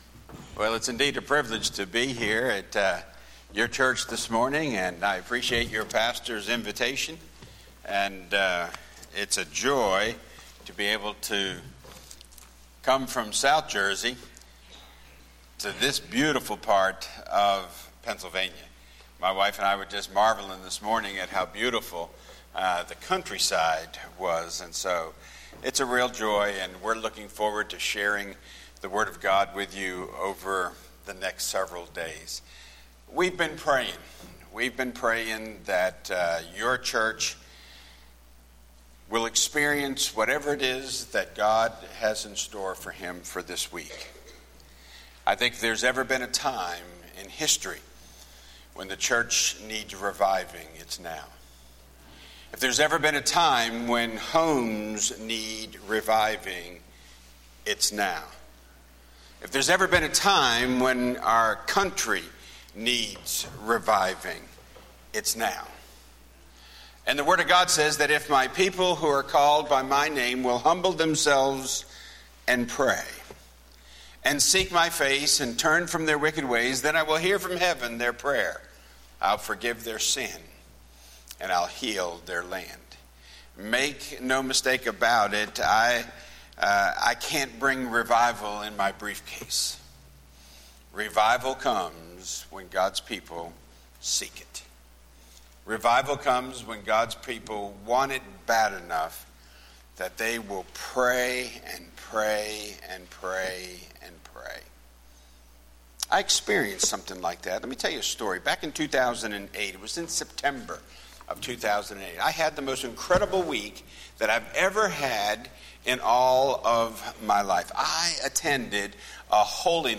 10:30AM Sunday Revival